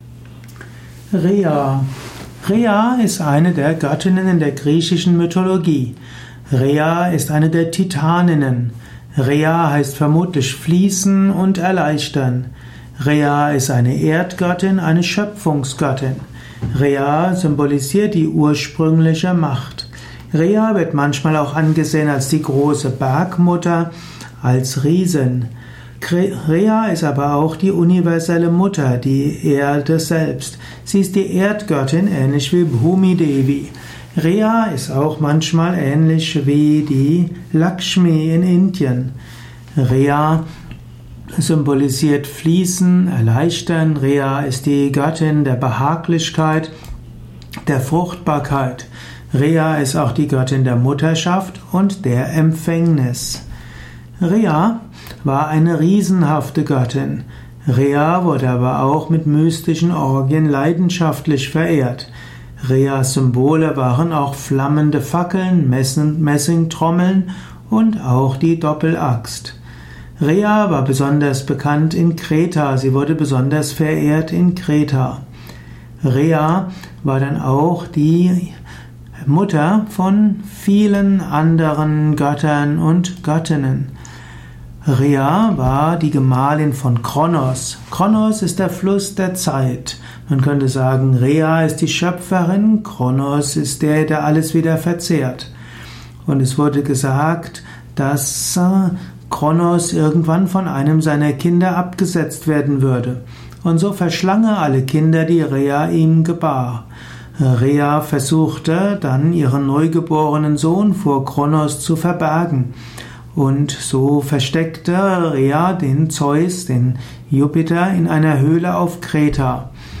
Ausführungen über die Stellung von Rhea in der griechischen Mythologie, im griechischen Götterhimmel. Welche Bedeutung hat Göttin Rhea vielleicht sogar in der heutigen Zeit? Dies ist die Tonspur eines Videos, zu finden im Yoga Wiki.